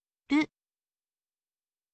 ออกเสียง: ru, รึ, รุ